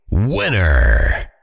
TowerDefense/Assets/Audio/SFX/Voiceover/winner.ogg at 0624b61fc46696b8e2f27c30c2e37902ae7c1782
winner.ogg